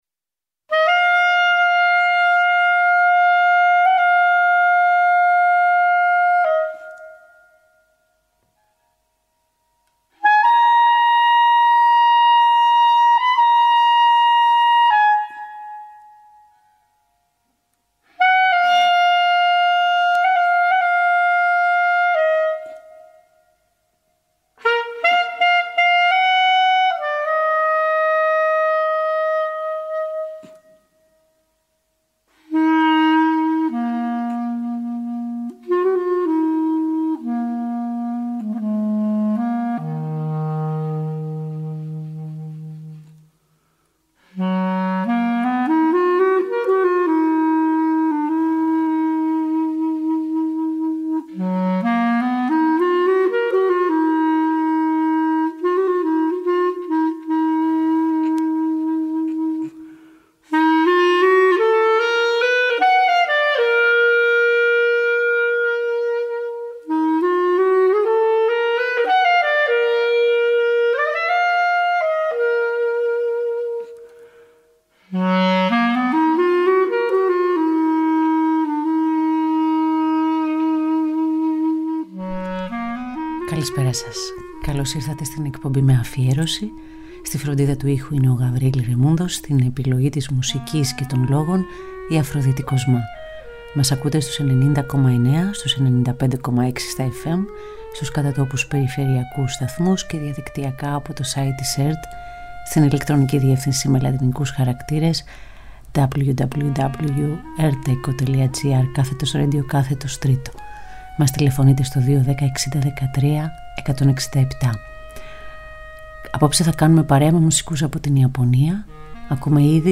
Κάθε Πέμπτη, ζωντανά από το στούντιο του Τρίτου Προγράμματος 90,9 & 95,6